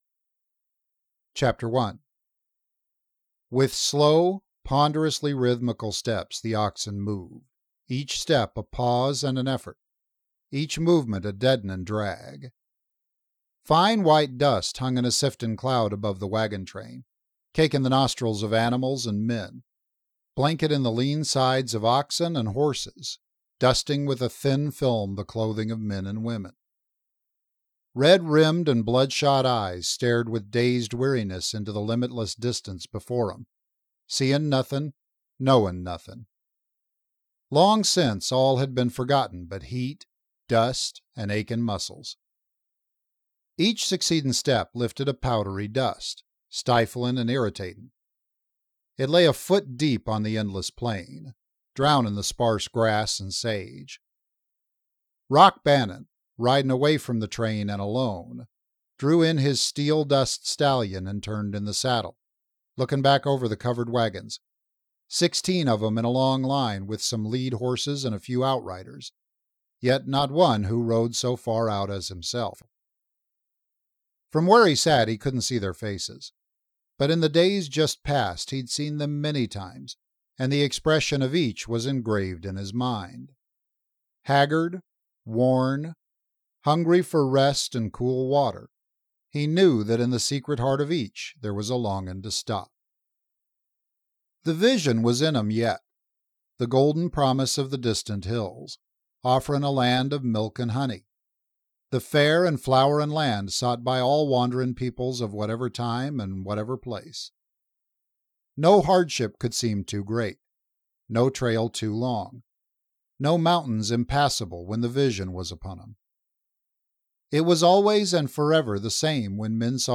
THIS IS AN AUDIOBOOK
Style: Westerns